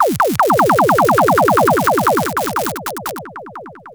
Lickshot03.wav